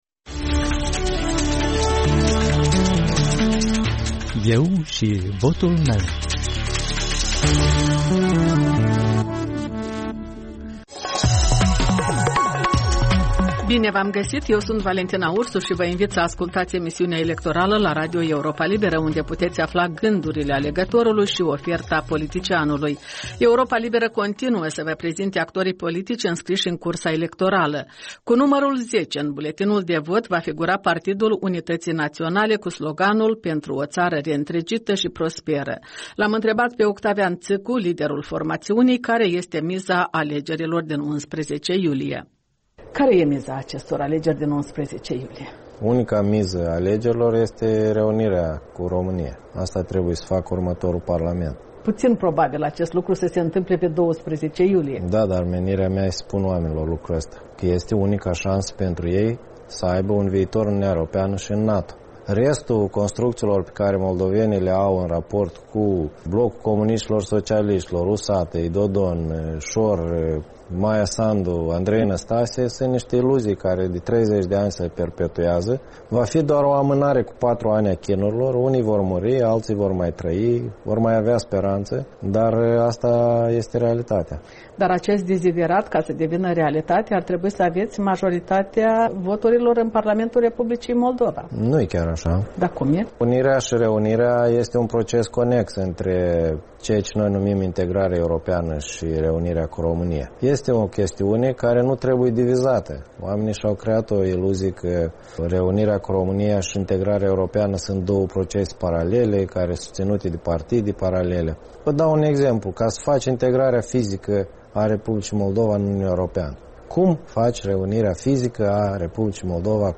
Radio Europa Liberă acoperă noul ciclu electoral cu interviuri, comentarii, analize și reportaje video din campania electorală.